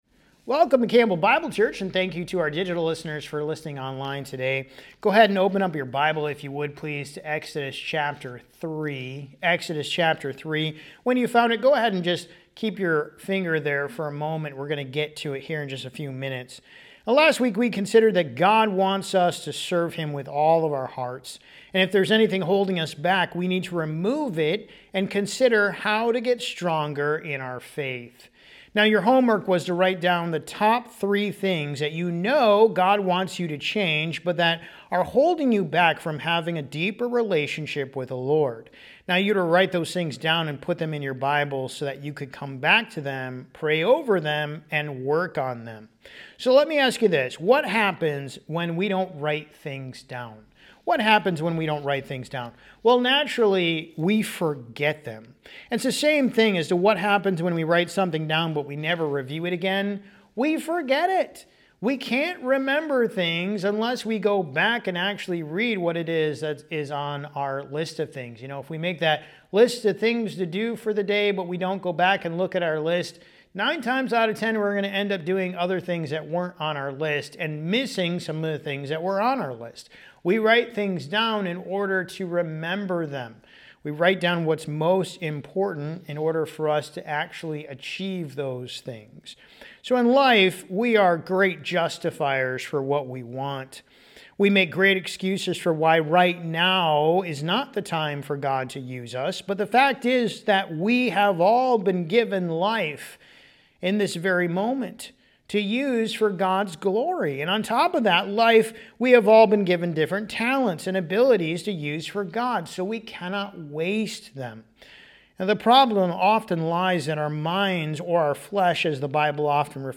Sermons | Campbell Bible Church